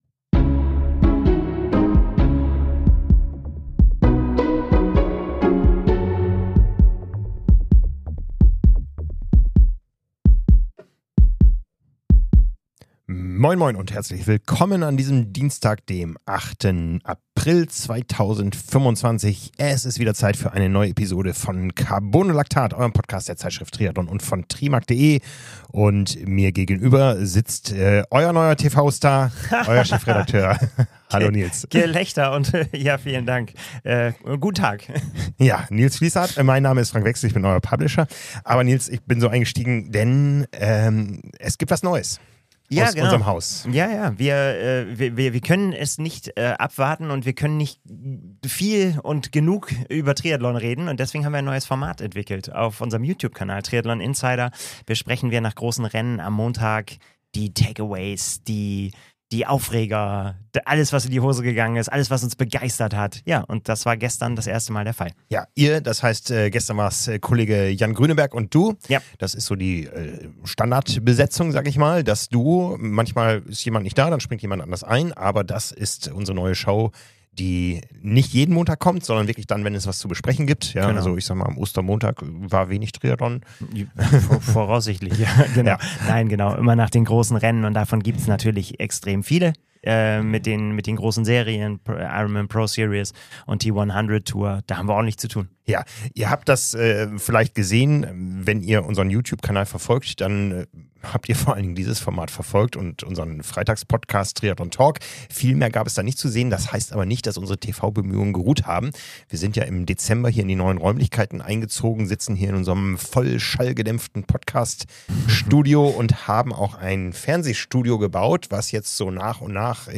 Zwei Experten aus der Redaktion sprechen über das aktuelle Triathlongeschehen.
Eine Persönlichkeit aus dem Triathlonsport im ausführlichen Gespräch.